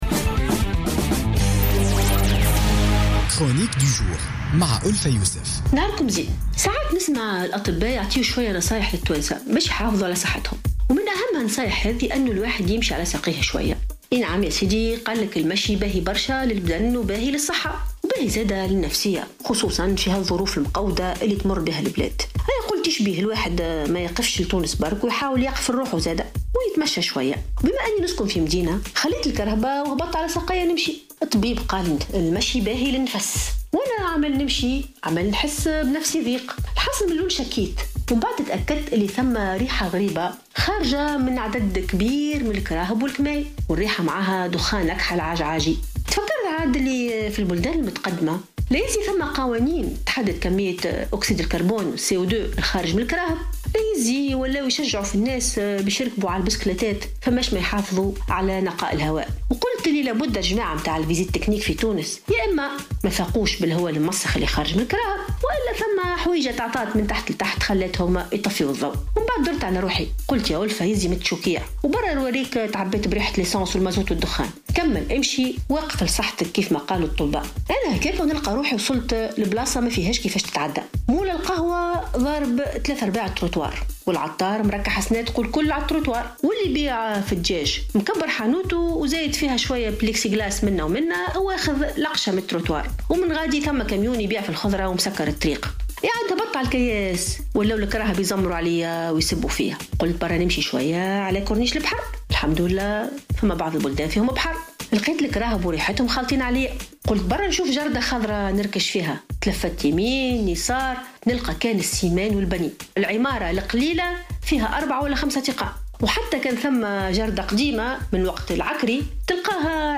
تطرقت الكاتبة ألفة يوسف في افتتاحية اليوم الجمعة 4 نوفمبر 2016 إلى ظاهرة التلوث التي اجتاحت المدن والإكتظاظ وخاصة زحف المقاهي واحتلالها للأرصفة في خرق وتعد صارخ على القوانين في تونس .